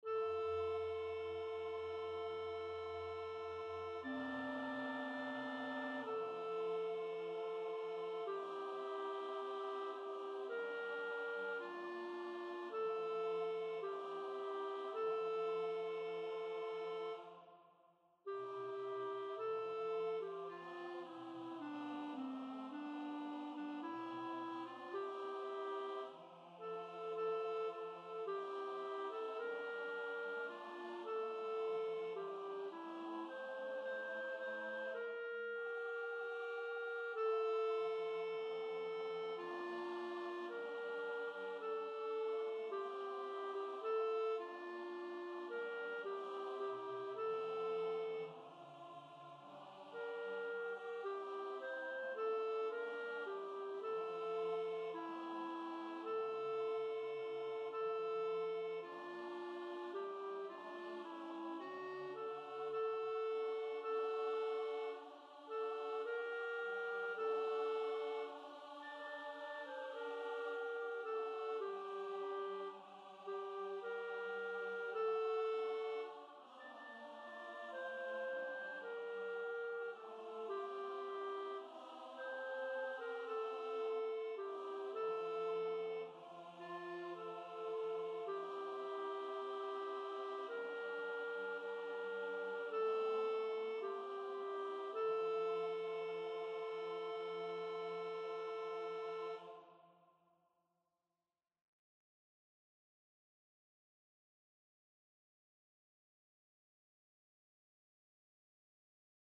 B146 de la Rue O Salutaris learning tracks
soprano